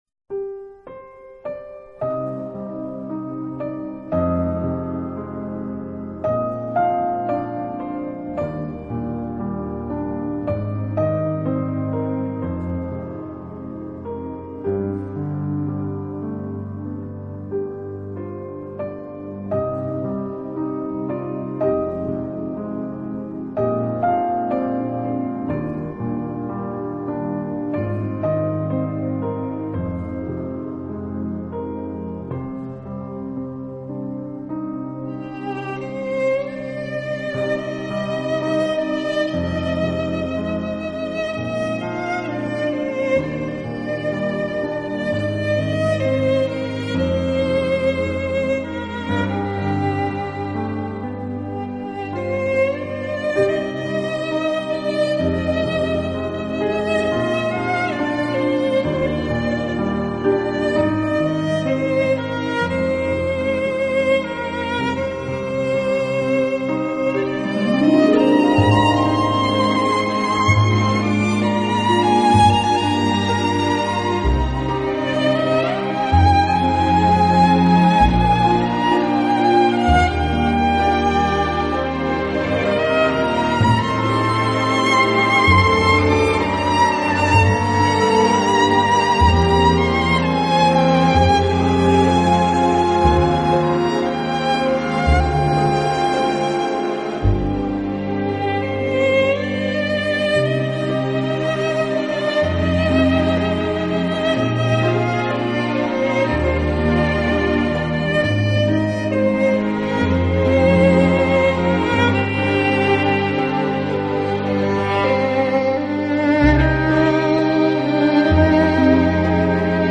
乐队融合了爱尔兰空灵飘渺的乐风以及挪威民族音乐及古典音乐，乐曲恬静深远，自然
流畅，使人不知不觉便已融入其中。